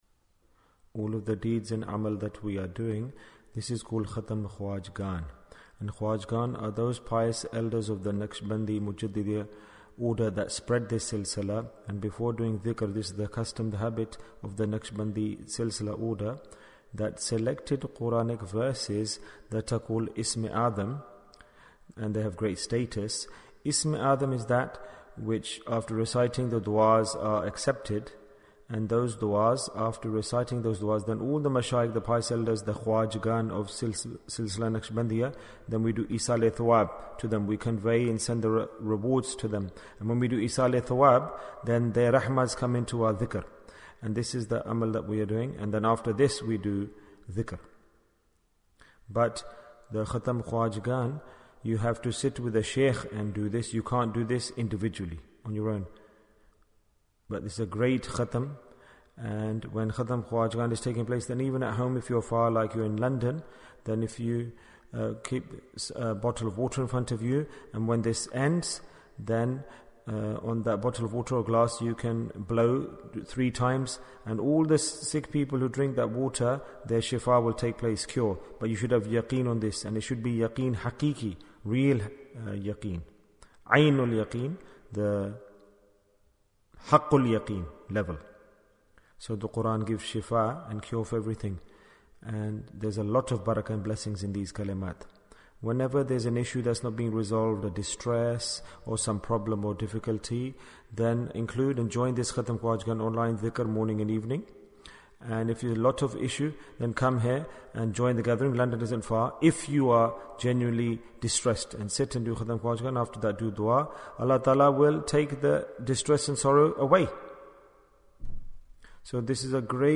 Ijtema Annual Majlis-e-Dhikr 2022 Fajr Bayan Bayan, 27 minutes26th December, 2022